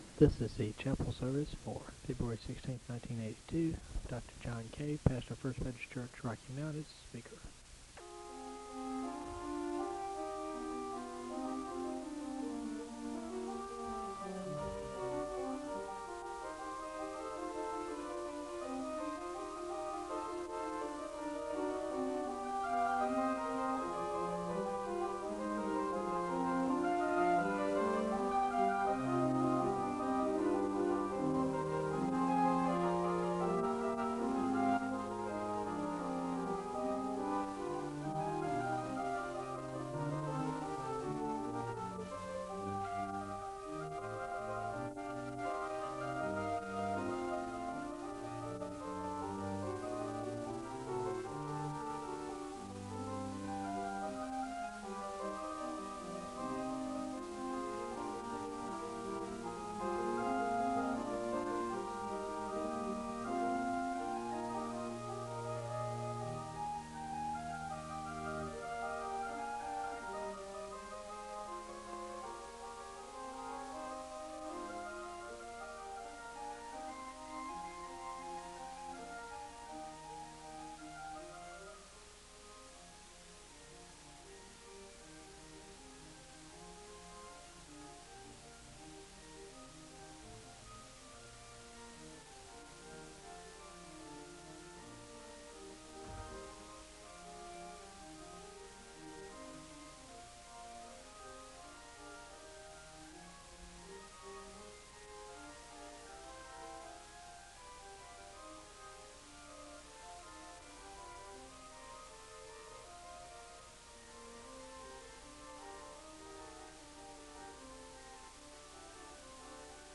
The service begins with organ music (00:00-05:10). The speaker gives a word of prayer (05:11-07:15).
The choir sings a song of worship (08:25-12:52).
The service ends with a word of prayer (32:06-33:07).